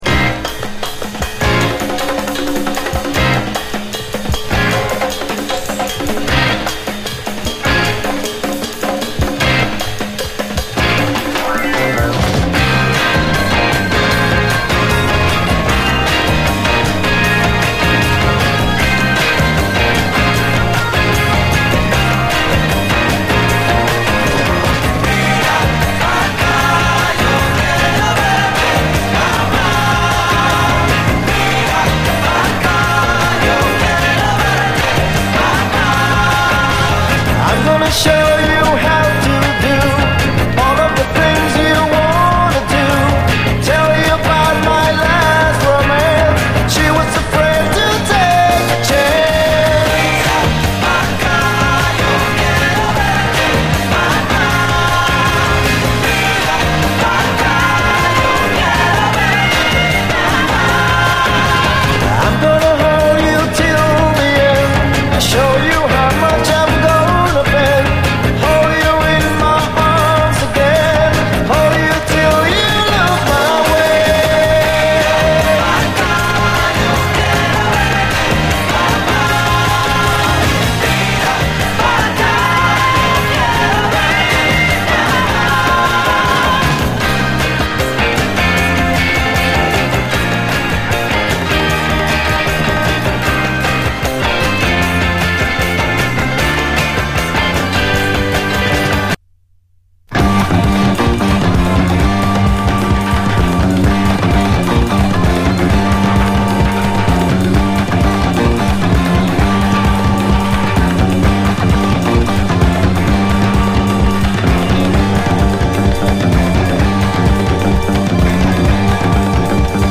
70's ROCK, LATIN, ROCK
内容はまさに王道を行く灼熱ラテン・ロック！豪快かつ爽快、フロア・ユースなダンス・チューン
暴走ラテン・ロック
ピュイーンとシンセが舞う異色の